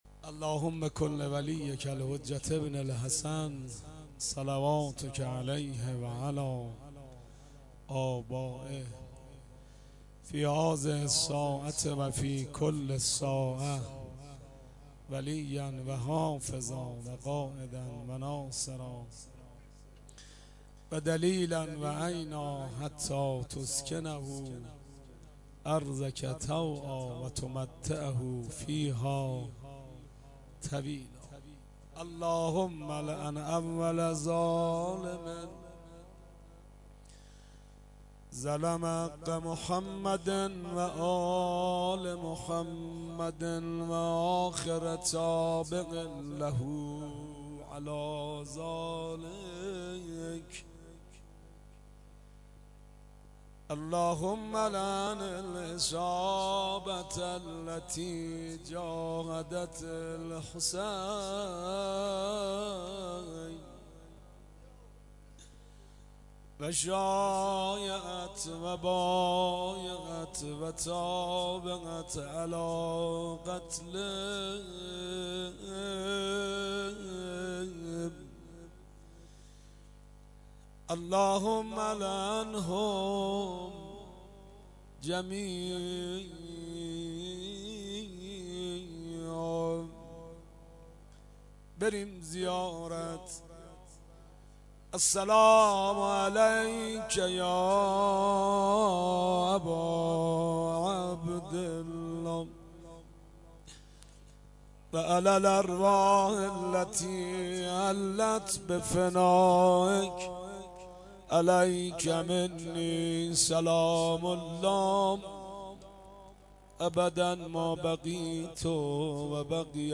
فایل های صوتی مراسم اربعین حسینی ۱۳۹۶